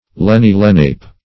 Search Result for " lenni-lenape" : The Collaborative International Dictionary of English v.0.48: Lenni-Lenape \Len`ni-Len*a"pe\ (l[e^]n`n[i^]-l[e^]n*[aum]"p[asl]), n. pl.
lenni-lenape.mp3